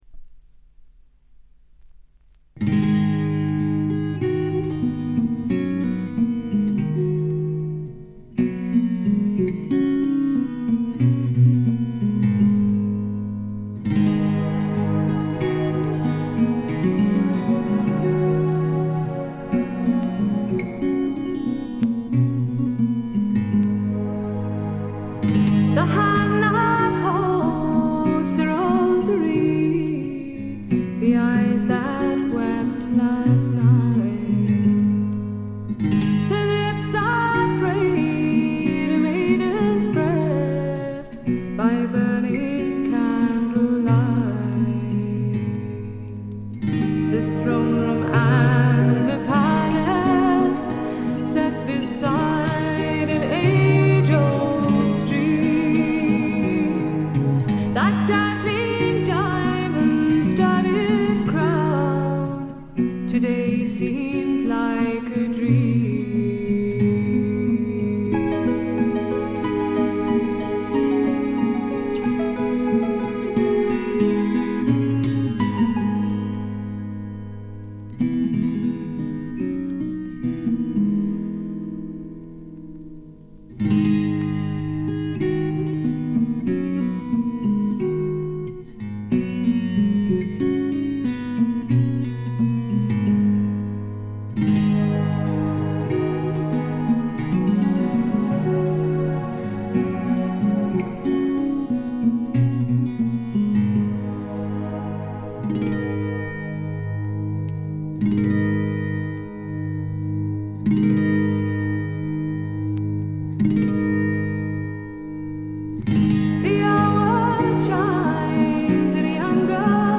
ごくわずかなノイズ感のみ。
試聴曲は現品からの取り込み音源です。
Guitar, Synth